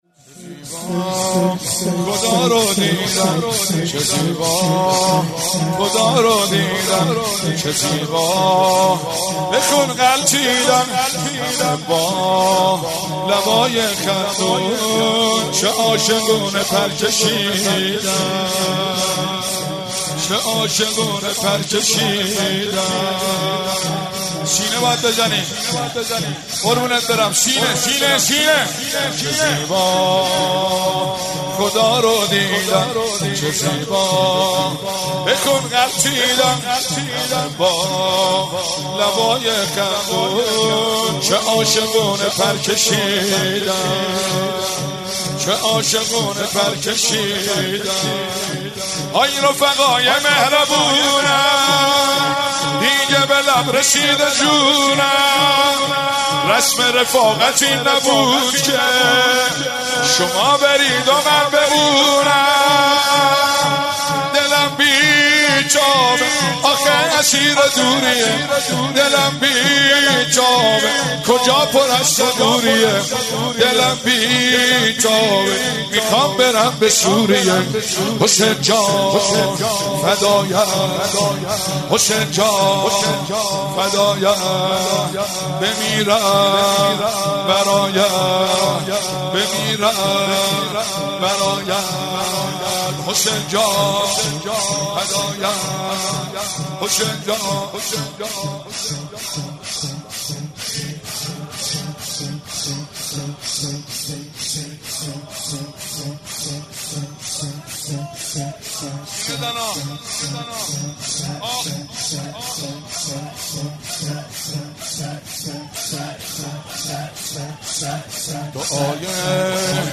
22 فروردین - هیئت فاطمیون قم - شور - چه زیبا خدارو دیدن